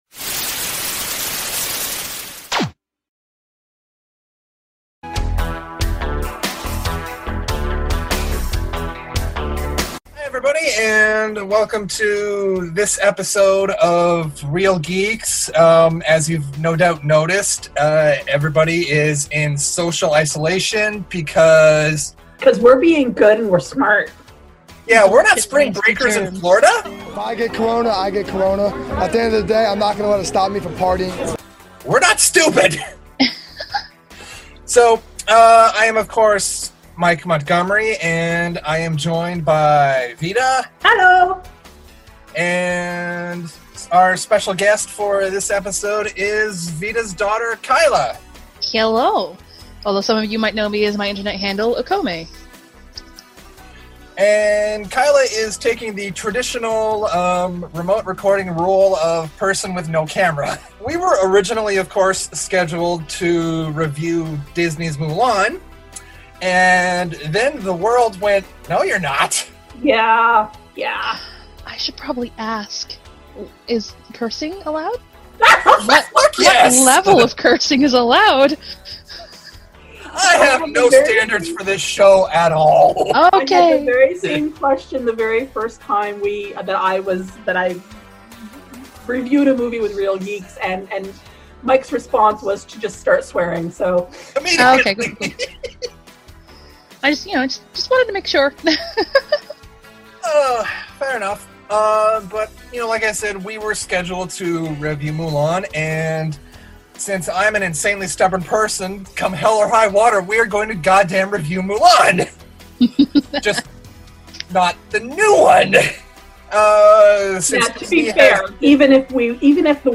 Originally recorded in Halifax, NS, Canada
Video: Zoom Video Conferencing